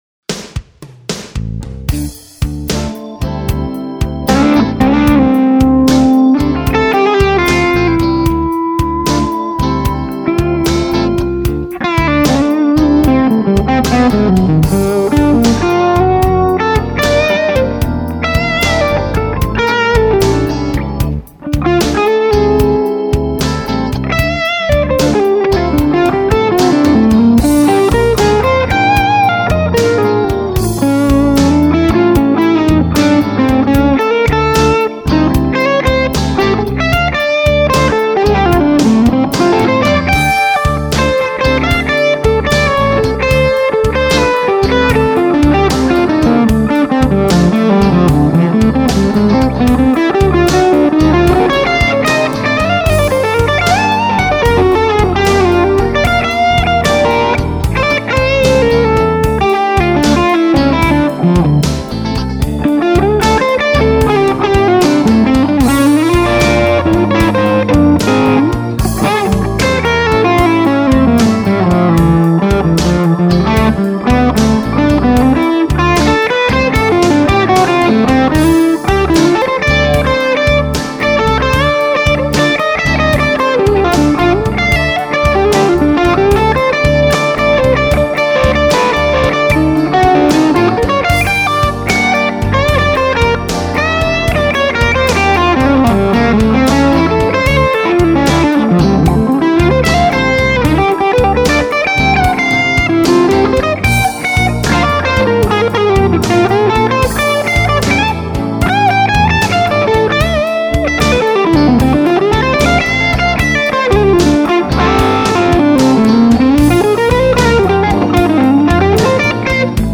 High Plate, Skyline Stack. Big ass bright cap on master. Loopalator and special cables. A little verb and delay added in mix. ANOS RCA in loopalator. A little clearer than the JJ I had in there. Amp has JJ ECC83S in all three slots. CSA on bridge humbucker.
G1265 speakers, multimiced with SM57 and R121 ribbon.
Both clips sounded great! Clip A sounded a bit "boomier" i suspect this was because of the JJ's which in my experience have a bit boomier bass response than some of the tubes im used to (ie. tungsol RI)
Seems like the upper mids are clearer on A. I see you are running it with the bludolater but is it going through the rest of the rack as well?